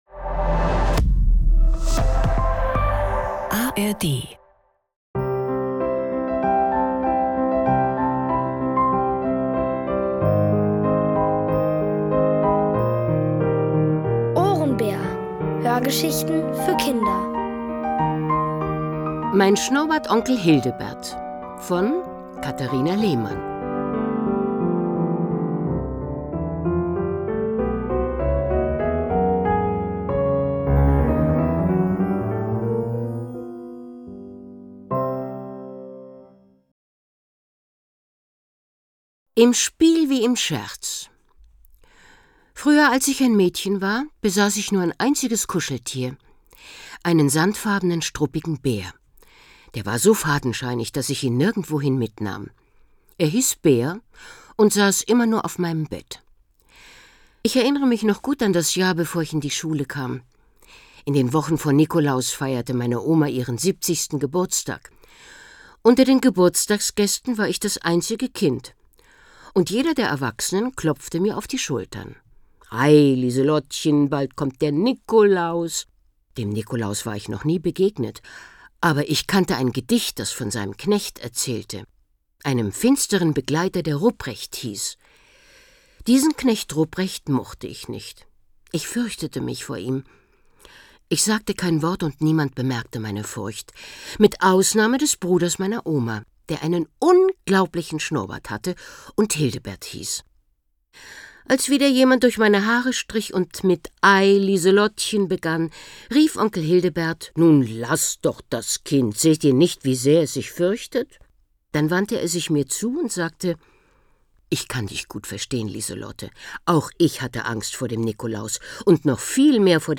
Alle 4 Folgen der OHRENBÄR-Hörgeschichte: Mein Schnurrbartonkel Hildebert von Katharina Lehmann. Es liest: Uta Hallant.